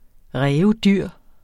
Udtale [ ˈʁεːvəˈdyɐ̯ˀ ] Betydninger meget dyr